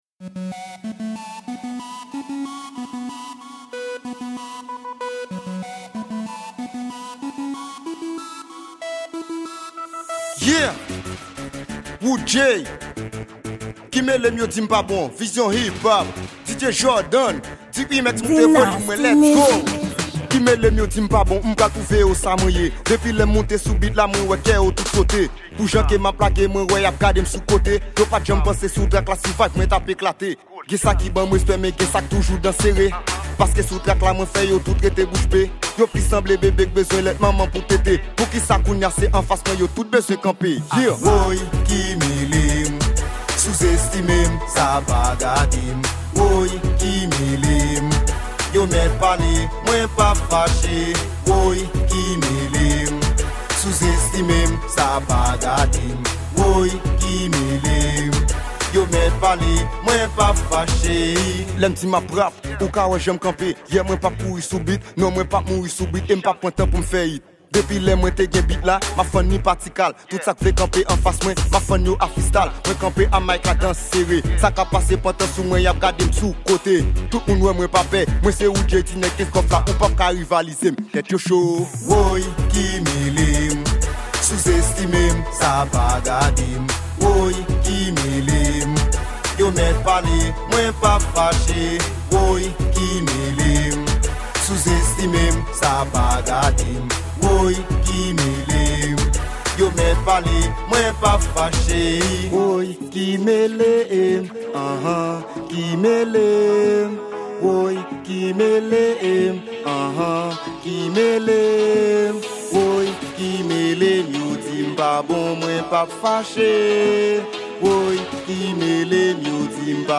Genre:Rap